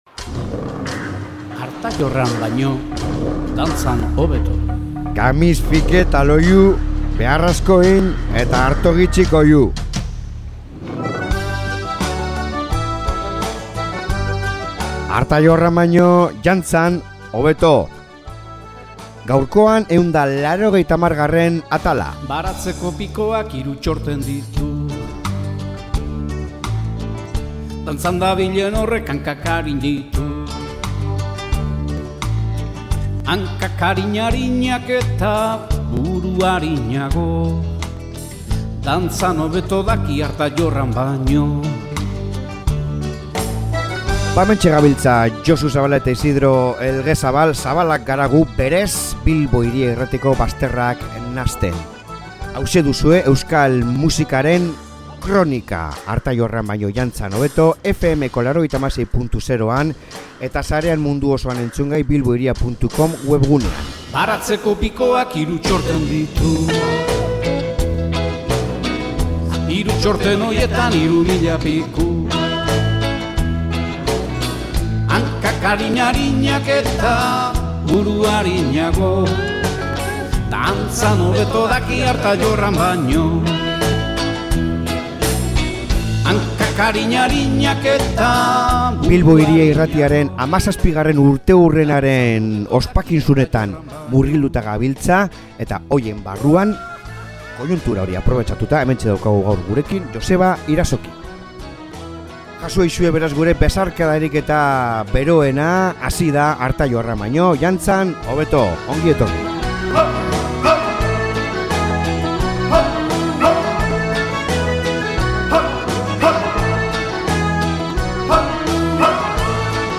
elkarrizketa ederra!